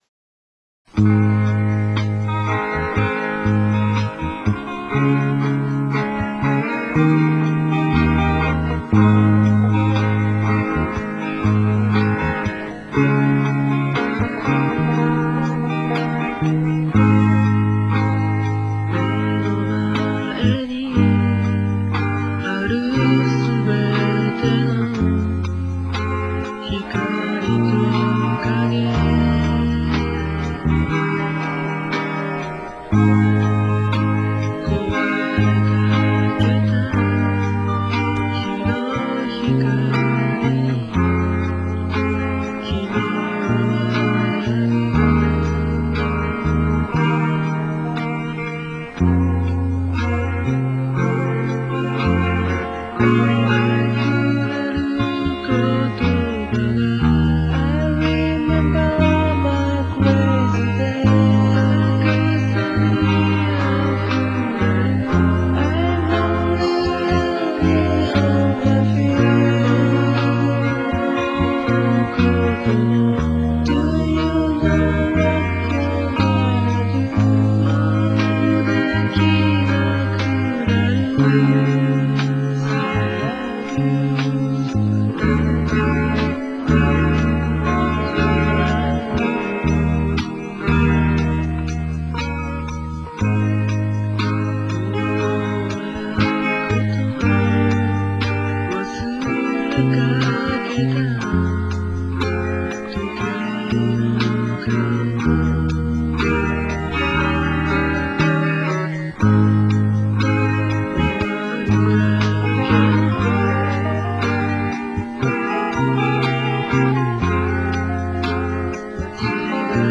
連休で暇だったので、下宿で一人でバラードを作ってみた。
ピンポン録音だと最初に入れたベースとかは最後の方ではほとんど聞こえなくなってました。